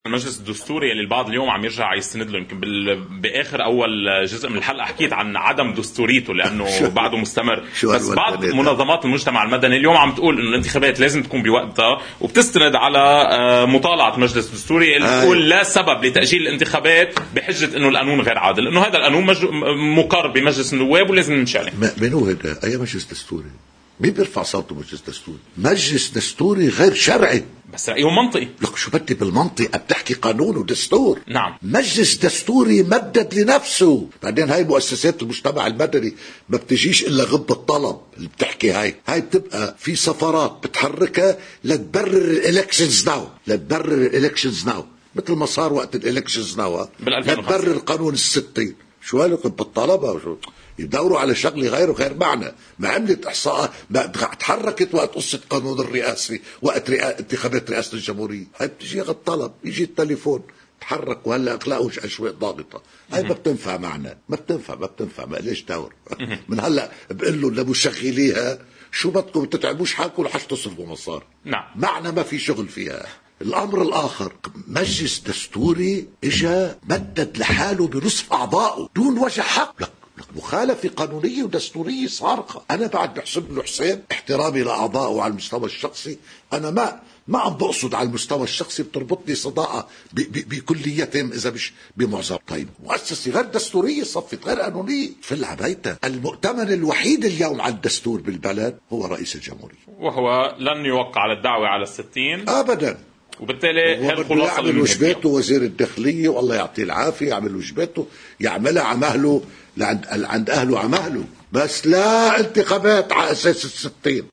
مقتطف من حديث رئيس مجلس النواب الأسبق ايلي الفرزلي لقناة الـ”OTV”: